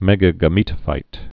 (mĕgə-gə-mētə-fīt)